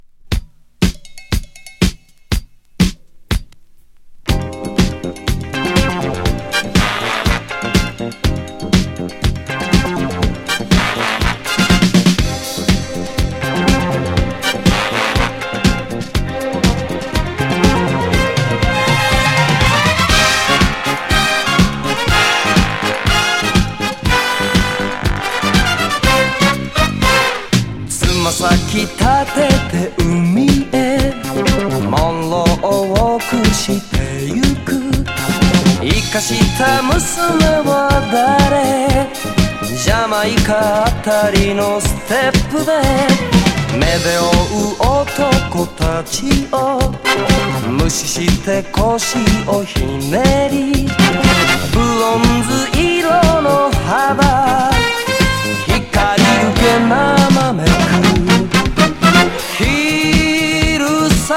エレクトロニクス・シンセ・トロピカル・ディスコ